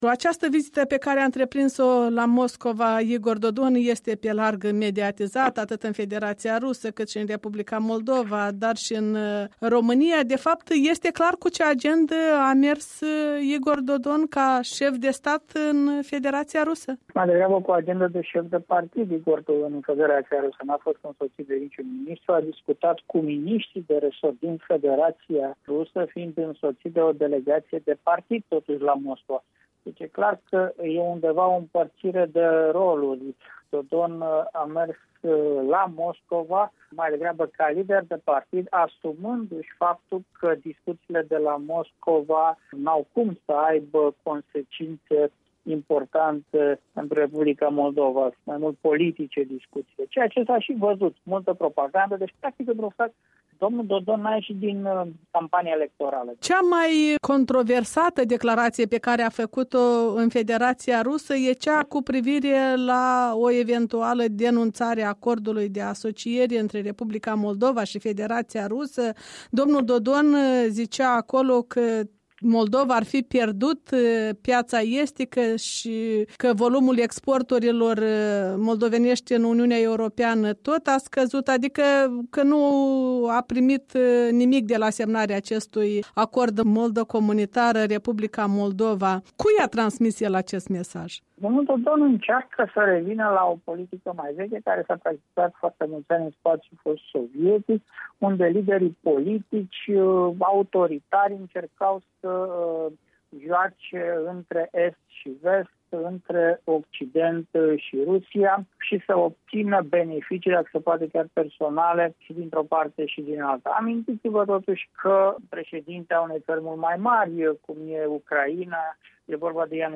Opiniile unui analist politic bucureștean specializat în spațiul post-sovietic.